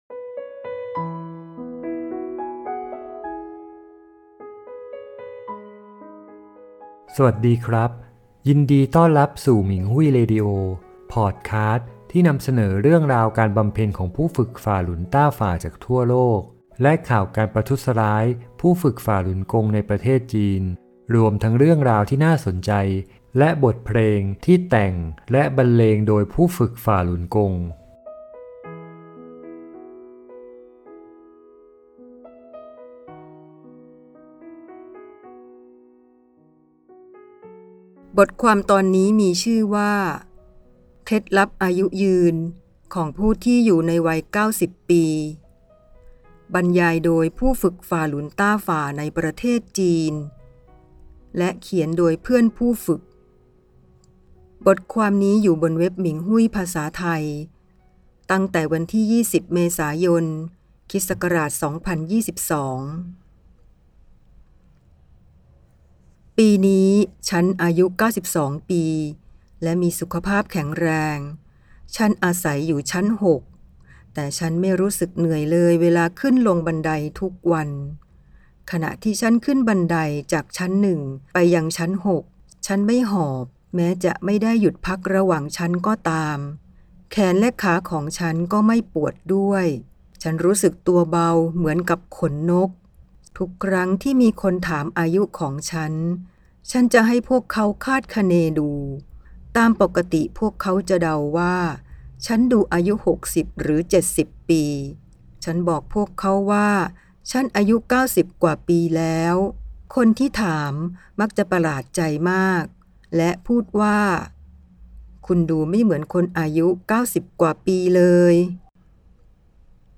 พอดแคสต์ 004 (การบำเพ็ญ): เคล็ดลับอายุยืนของผู้ที่อยู่ในวัย 90 ปี - บรรยายโดยผู้ฝึกฝ่าหลุนต้าฝ่าในประเทศจีน และเขียนโดยเพื่อนผู้ฝึก - บทความแลกเปลี่ยนประสบการณ์บนเว็บหมิงฮุ่ย